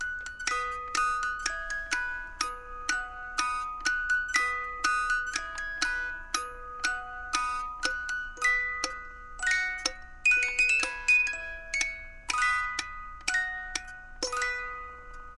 Здесь собраны лучшие композиции с нежным механическим звучанием, которые подойдут для релаксации, творчества или создания особой атмосферы.
Нежная мелодия на музыкальной шкатулке